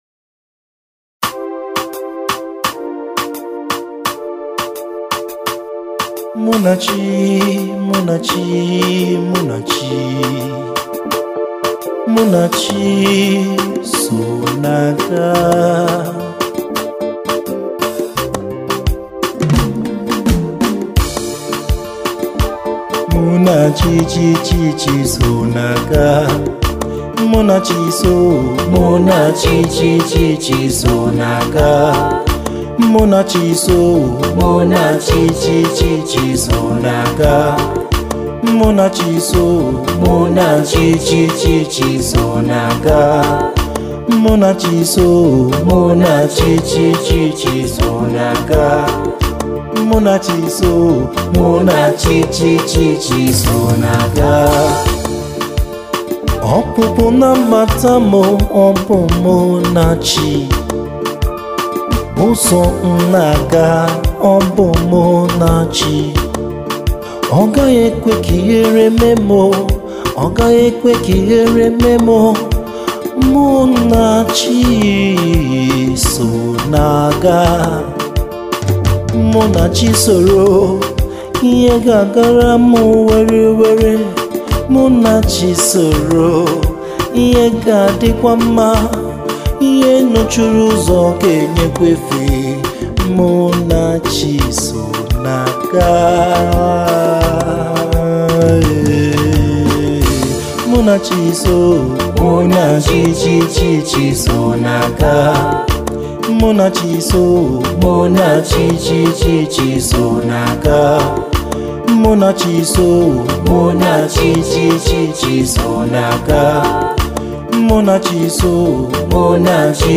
Here’s a brand-new gospel song that will bless your soul!
With heartfelt lyrics and uplifting instrumentation